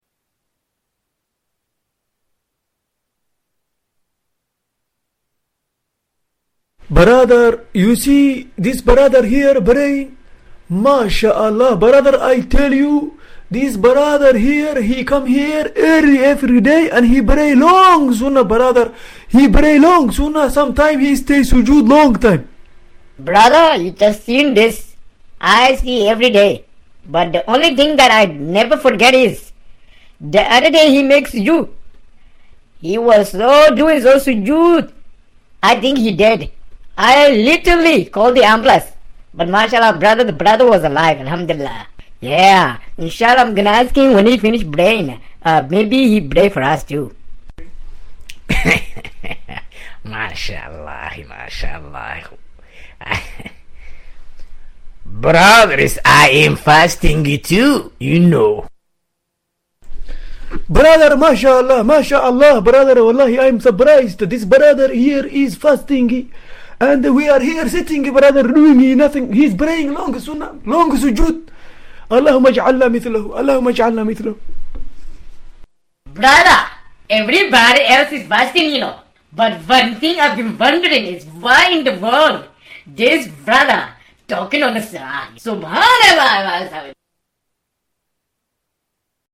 This reminder captures a humorous yet thought-provoking scene about a brother who prays long and hard in the mosque, prompting both admiration and an important lesson about sincerity.